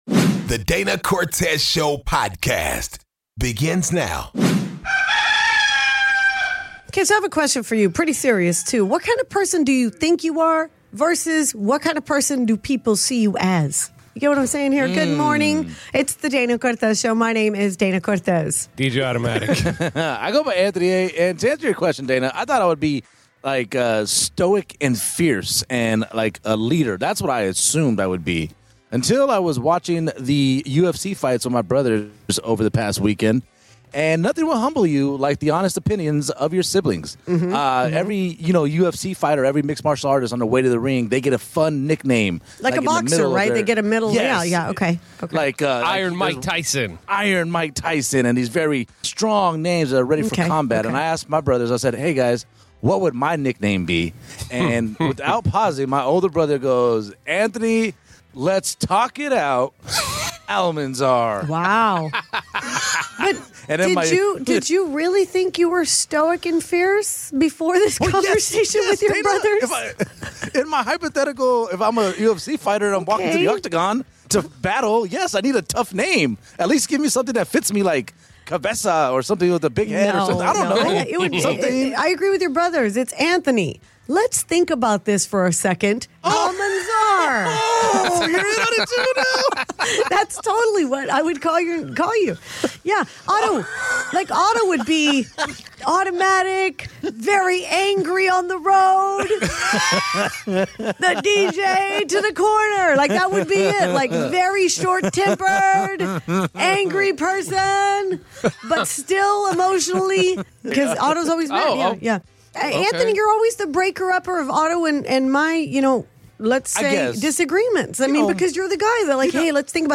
We had a new DCS listener call in to ask where she can meet some new ppl since she is new here. It turned into how did you meet your person.